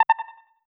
FUI Ping Triplet Echo.ogg